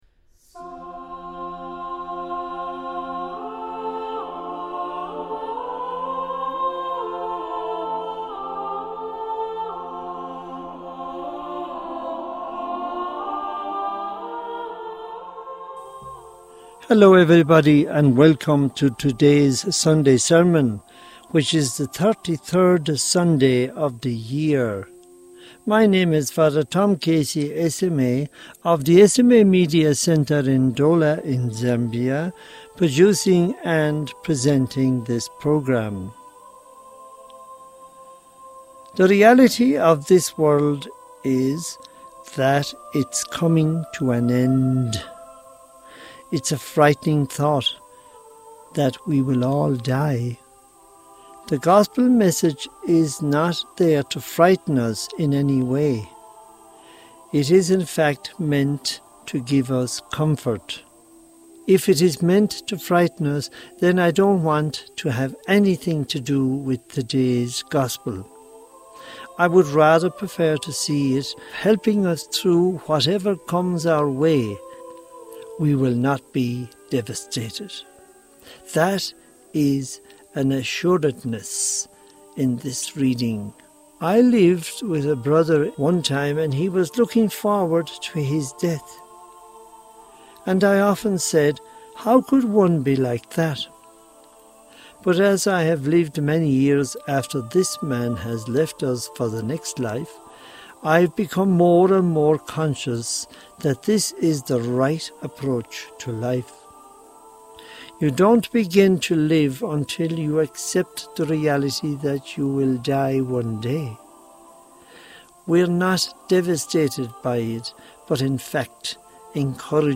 Homily for the 33rd Sunday of Ordinary Time 2025 | Society of African Missions